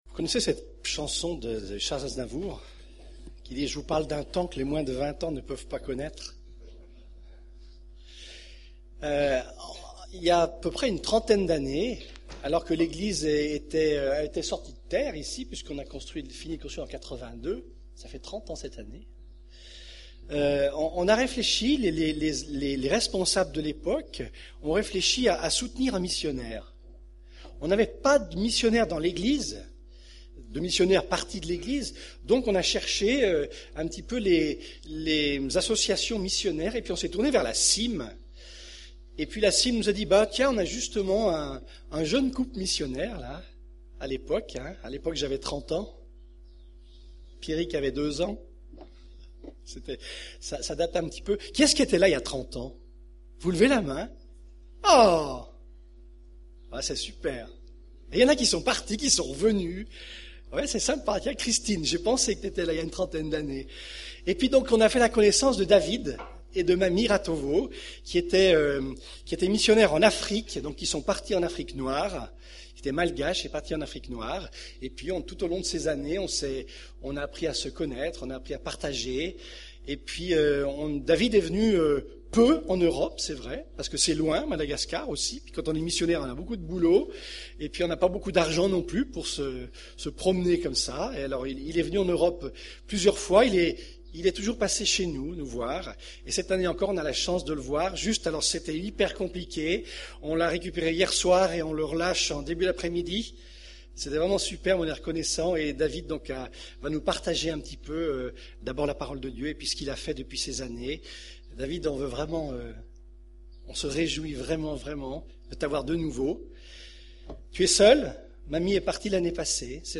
Culte du 29 avril